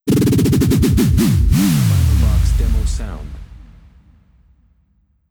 “Oscilloth” Clamor Sound Effect
Can also be used as a car sound and works as a Tesla LockChime sound for the Boombox.